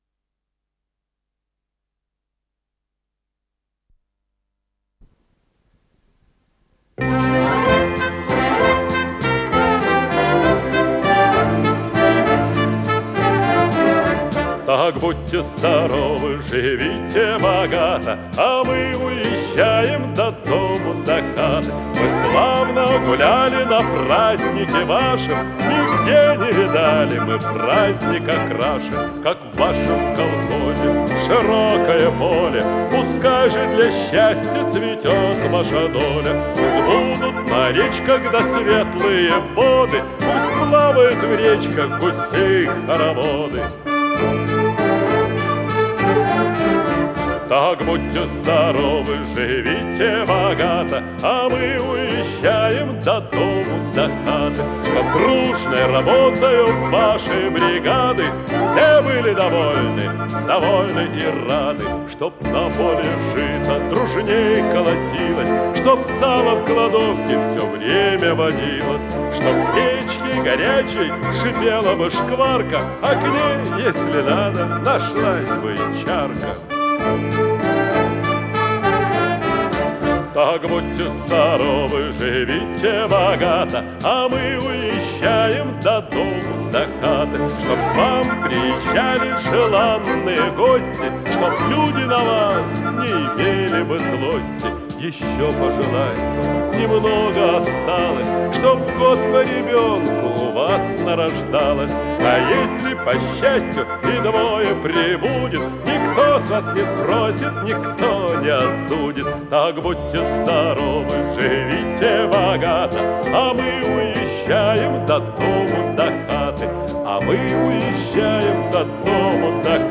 с профессиональной магнитной ленты
баритон